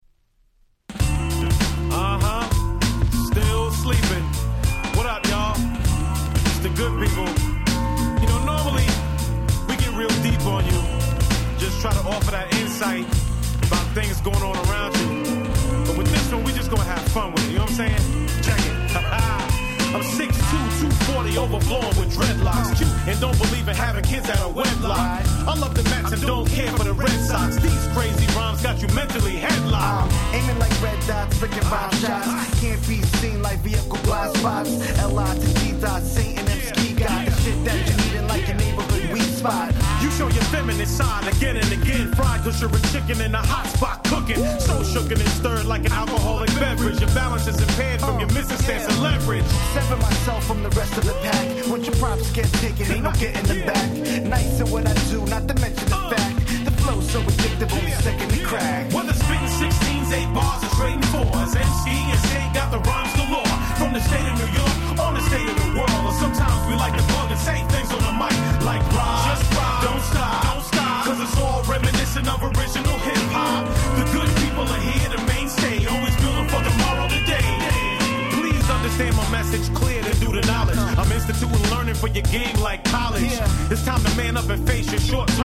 07' Nice Hip Hop !!
詳細不明の黒人白人の混成ユニット。
Jazzyなネタ使いのオリジナルもバッチリ！